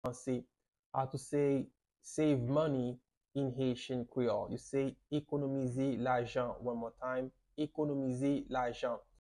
How to say "Save Money" in Haitian Creole - "Ekonomize Lajan" pronunciation by a native Haitian teacher
“Ekonomize lajan” Pronunciation in Haitian Creole by a native Haitian can be heard in the audio here or in the video below:
How-to-say-Save-Money-in-Haitian-Creole-Ekonomize-Lajan-pronunciation-by-a-native-Haitian-teacher.mp3